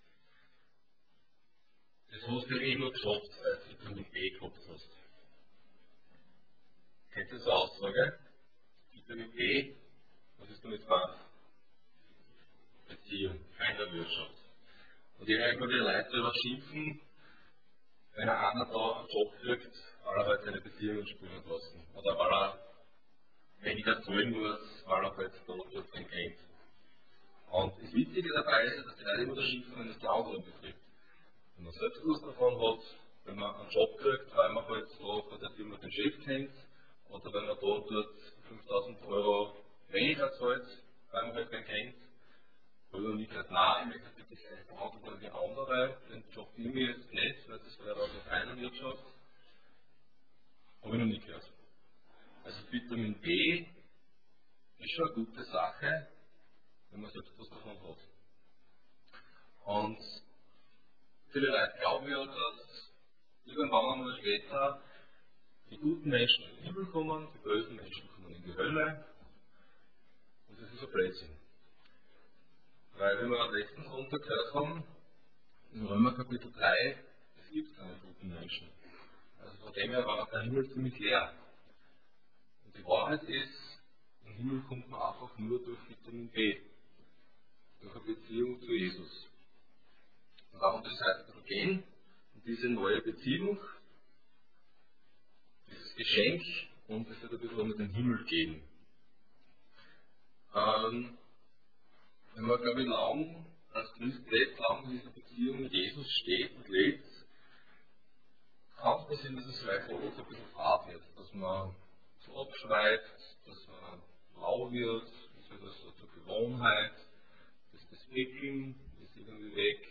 Passage: John 11:1-45 Dienstart: Sonntag Morgen